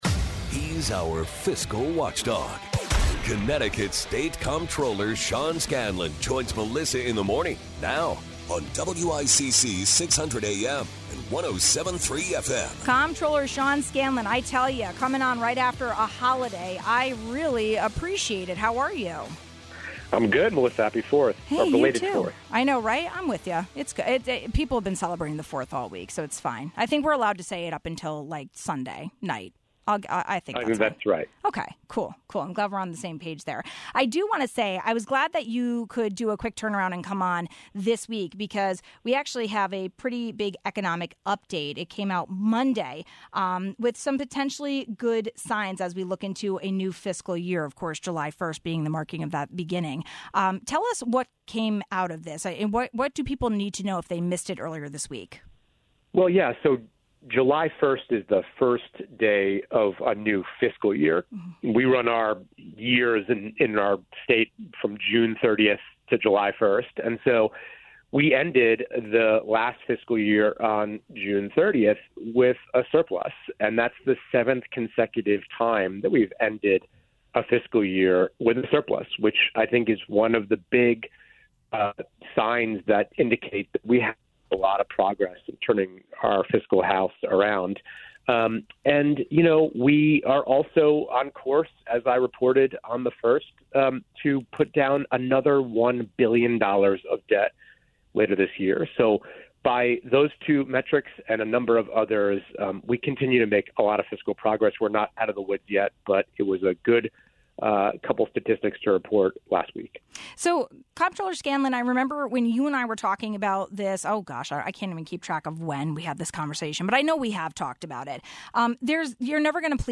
The latest economic update came out Monday July first with some good signs as the new fiscal year begins. What are some big takeaways, and do we need to adjust our fiscal guardrails moving forward? We talked about all of that with Comptroller Sean Scanlon.